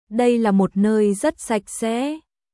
ダイ ラ モッ ノイ ザット サック セー🔊